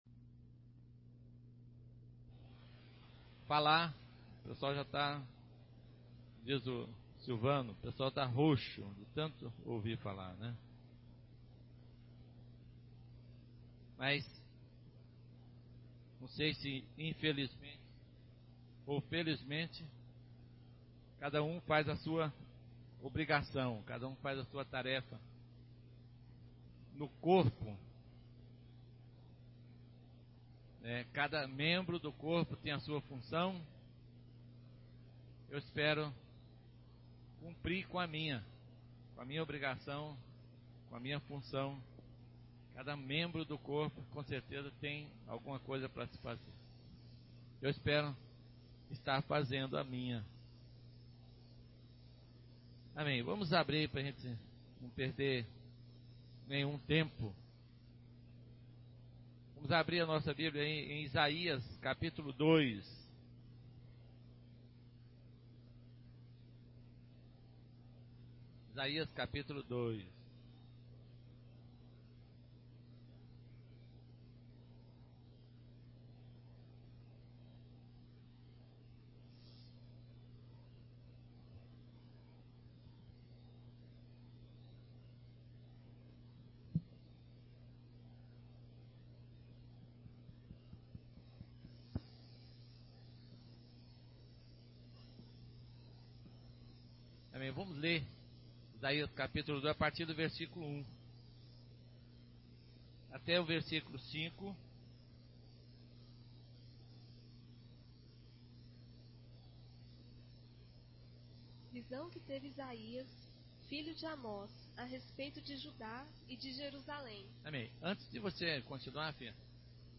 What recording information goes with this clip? Reunião 03-12-14 – A igreja será a referencia para o julgamento das nações – Domingo manhã